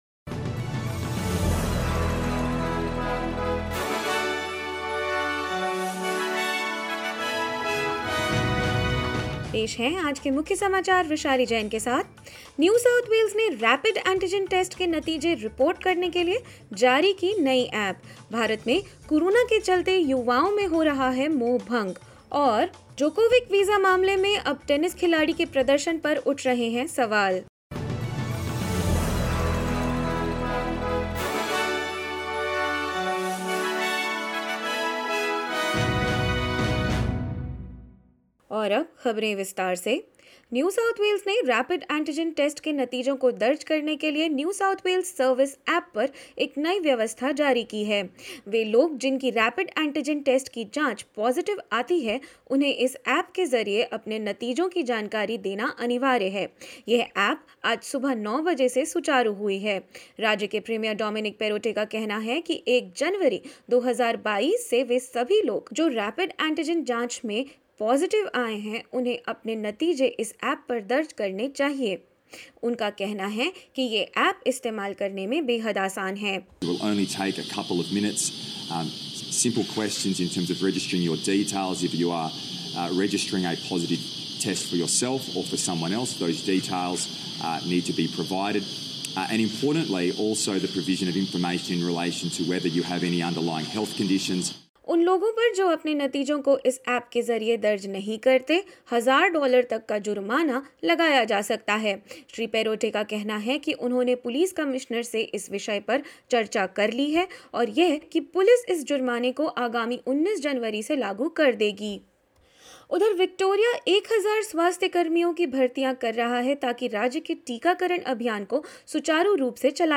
In this latest SBS Hindi news bulletin: New South Wales launches a reporting app for people who have a positive Rapid Antigen test; World Economic Forum finds in a survey that Indian youth is suffering from disconnect and digital inequality and more.